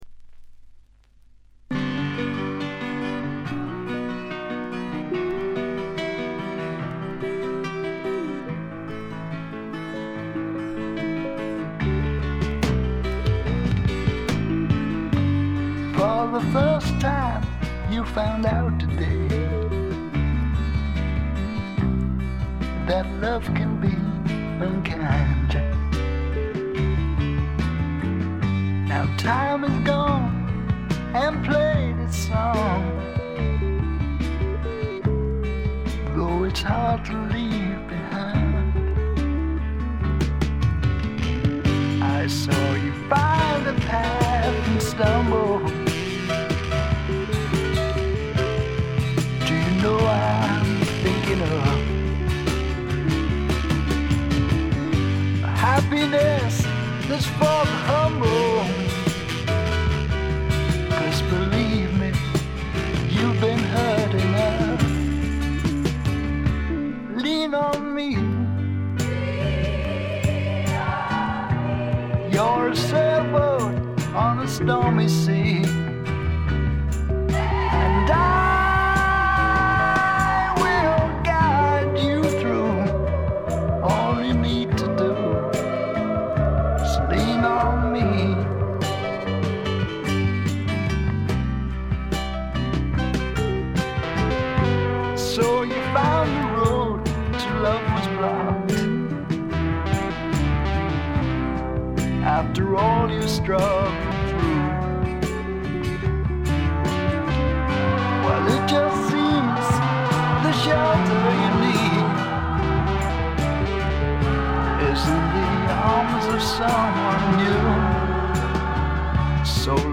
搾り出すような激渋のヴォーカルがスワンプ・サウンドにばっちりはまってたまりません。
試聴曲は現品からの取り込み音源です。